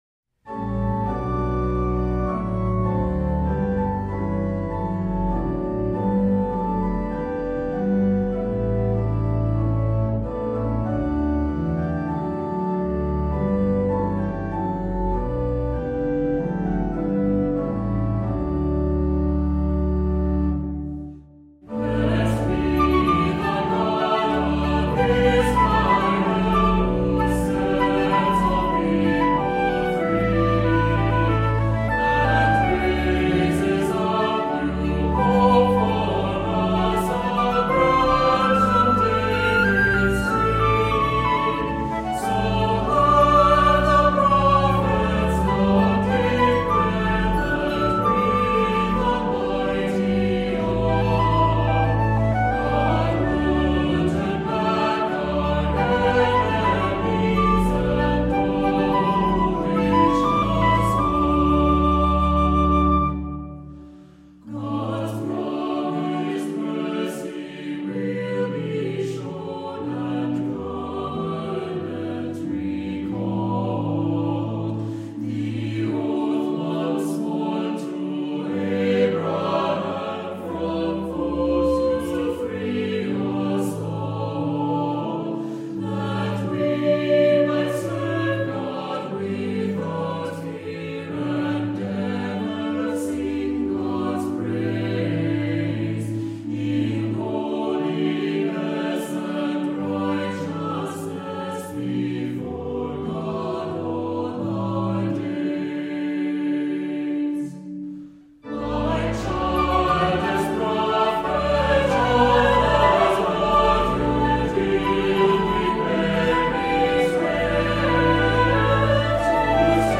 Voicing: SATB, assembly,Unison